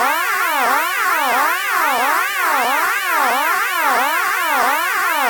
Stereo Wow Alarm Loop
Alien Ambient Audio Background BaDoink Dub Dubstep Effect sound effect free sound royalty free Sound Effects